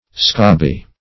scobby - definition of scobby - synonyms, pronunciation, spelling from Free Dictionary Search Result for " scobby" : The Collaborative International Dictionary of English v.0.48: Scobby \Scob"by\, n. The chaffinch.